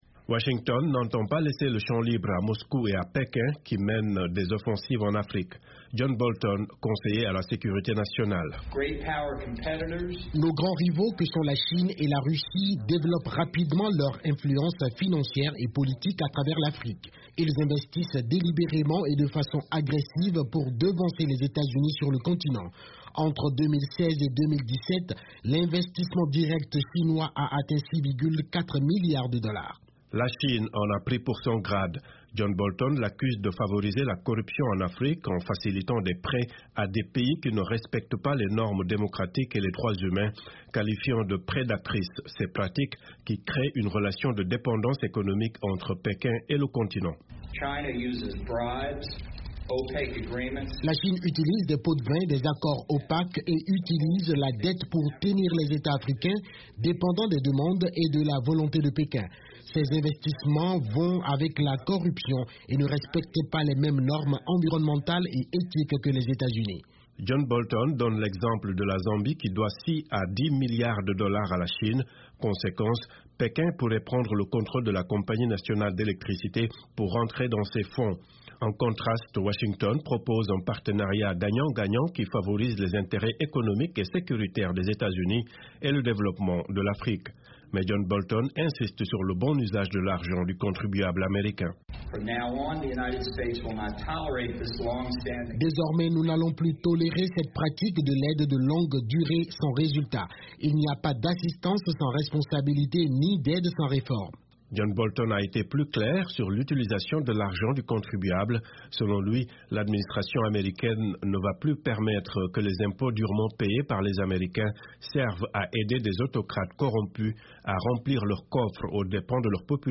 "Malheureusement, des milliards et des milliards de dollars des contribuables américains n'ont pas abouti aux résultats escomptés", a estimé John Bolton dans un discours devant le cercle de réflexion conservateur Heritage Foundation à Washington.